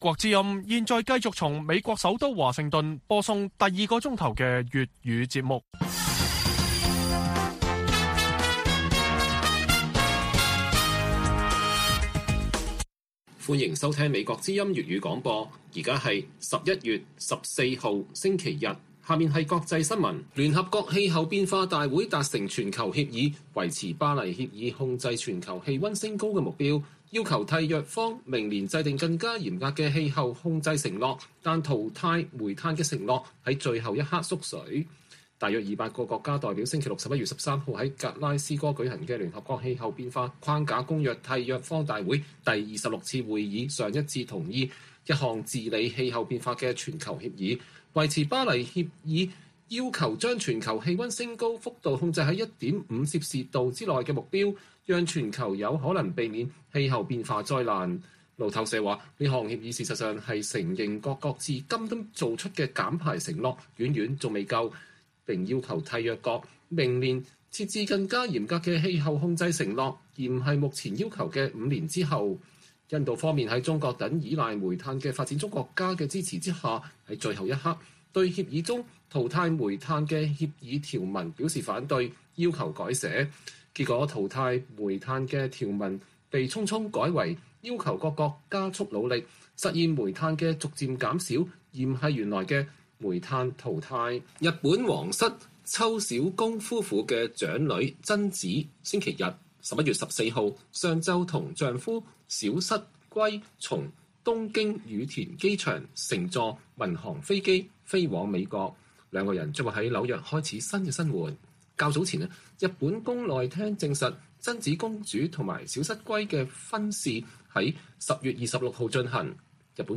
粵語新聞 晚上10-11點: 東西方話語權之爭 中國正在打一場逆風球